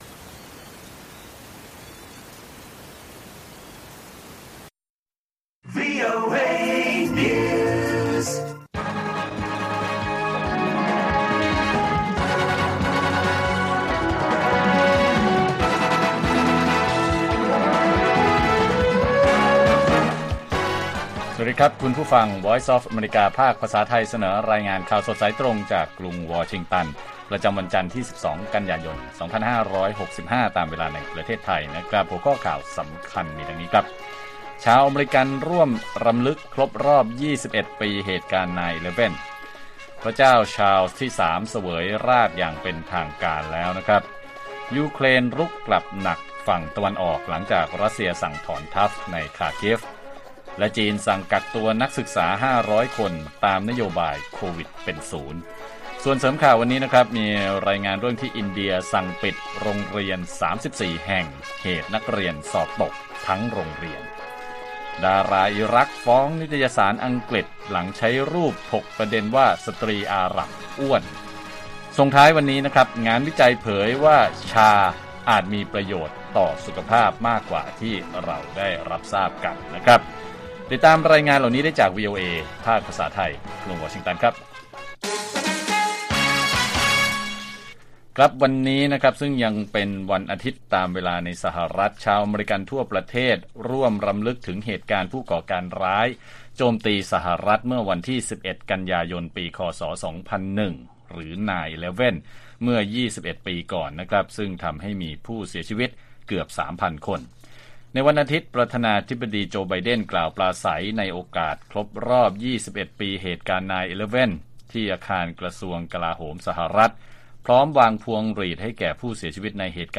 ข่าวสดสายตรงจากวีโอเอ ภาคภาษาไทย 8:30–9:00 น. วันจันทร์ ที่ 12 ก.ย. 2565